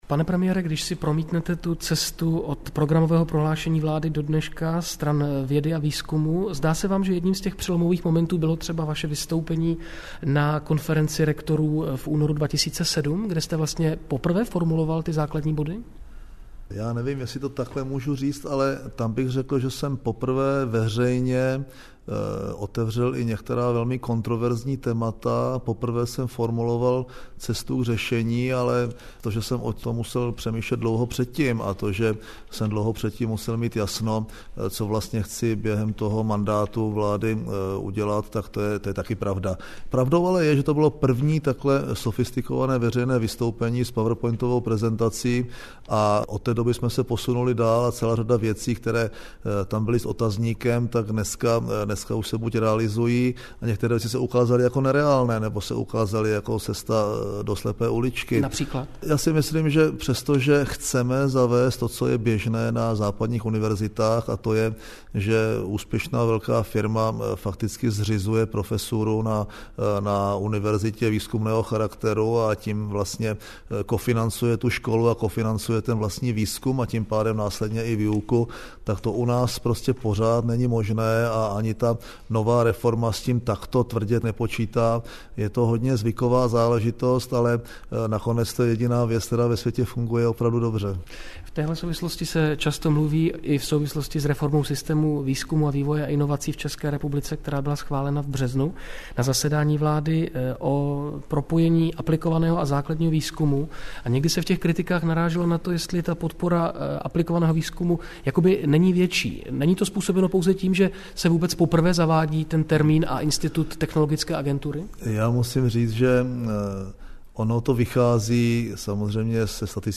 Český rozhlas Leonardo zveřejnil rozhovor o vědě a výzkumu s premiérem M. Topolánkem
Půlhodinový rozhovor, ve kterém premiér odpovídá na otázky spojené s novým systémem podpory a hodnocení české vědy, byl odvysílán v 10.30 v rámci magazínu Monitor.